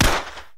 submachine_fire.ogg